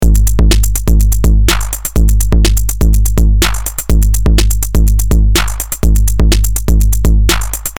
Here I have run the loop through the AR Mk1 overdrive, distorion and both together.
Both master distortion and voice overdrive: